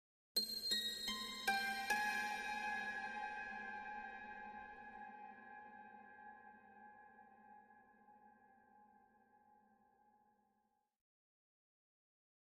High Strings
Harmonic Plucks Mystic Harmonic Plucks 5 Note Arpeggio 2